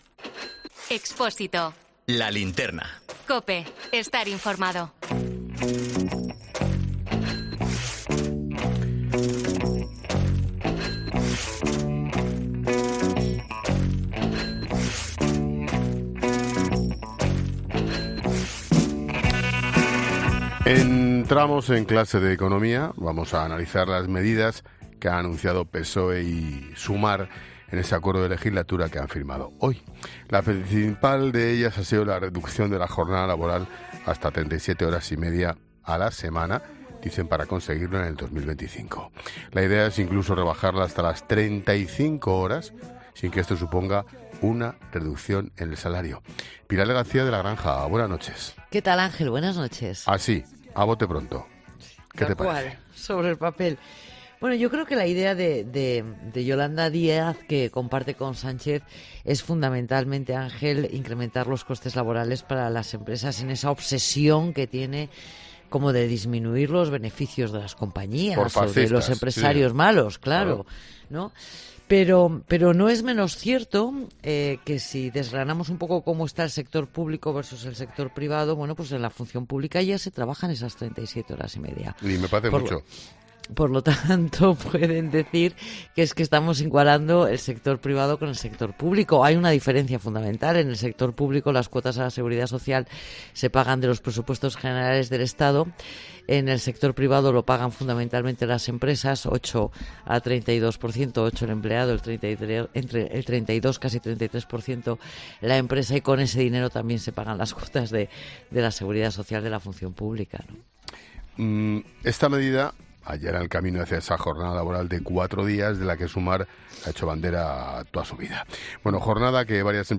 Ángel Expósito analiza con expertos económicos el acuerdo para reducir las horas de trabajo semanales para dentro de dos años